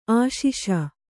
♪ āśiṣa